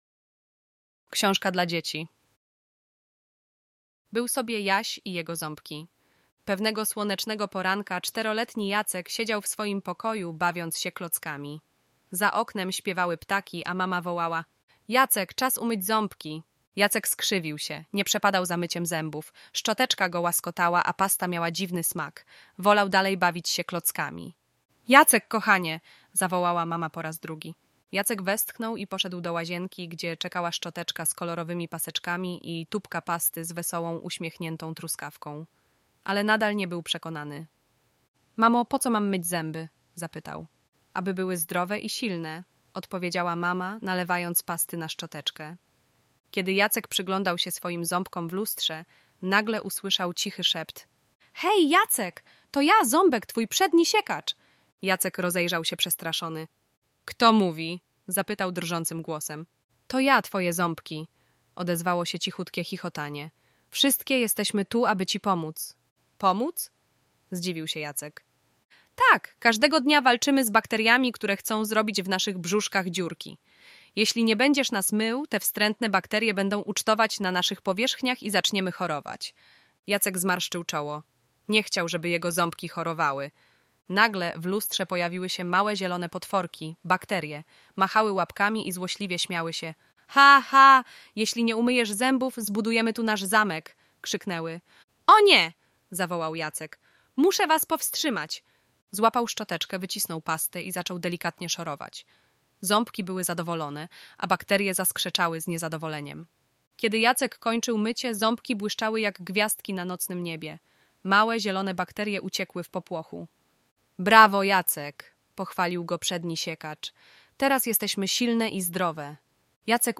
Audiobook – Bajka „Był Sobie Jacek i Jego Ząbki” (MP3)